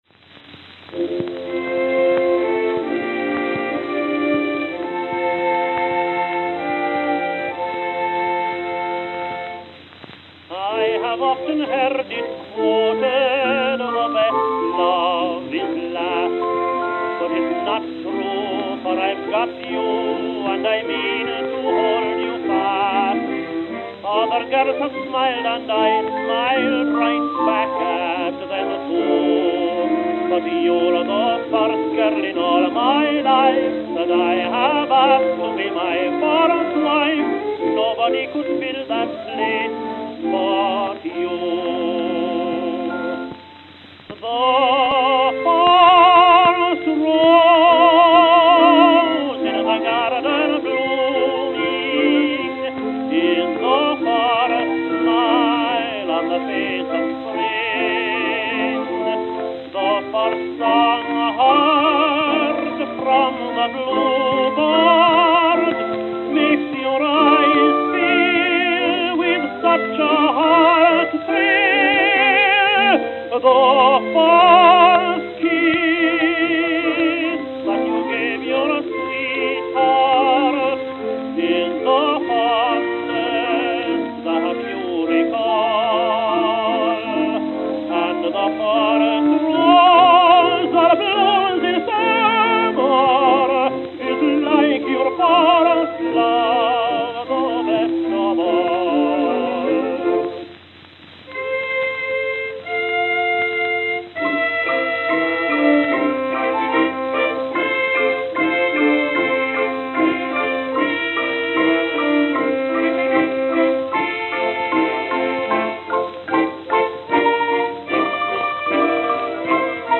Camden, New Jersey
Note: Worn.